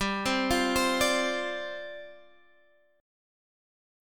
Listen to G7sus4 strummed